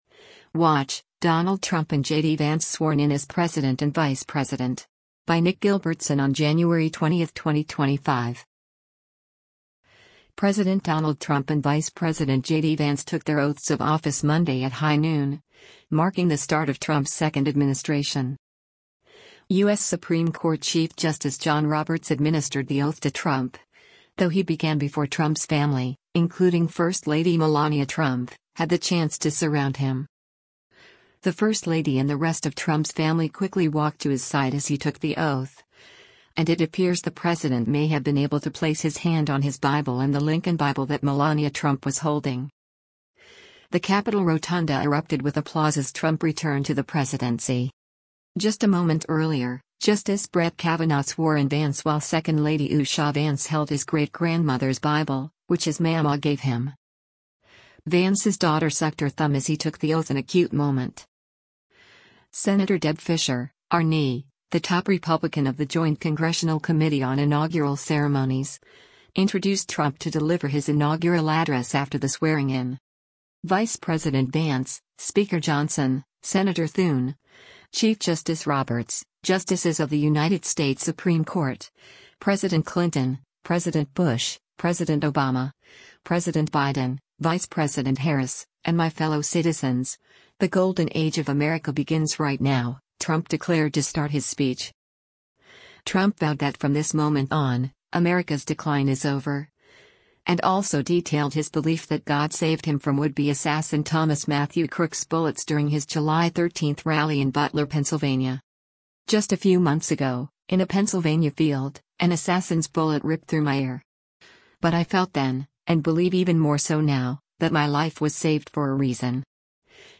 President Donald Trump and Vice President JD Vance took their oaths of office Monday at high noon, marking the start of Trump’s second administration.
U.S. Supreme Court Chief Justice John Roberts administered the oath to Trump, though he began before Trump’s family, including First Lady Melania Trump, had the chance to surround him.
The Capitol rotunda erupted with applause as Trump returned to the presidency.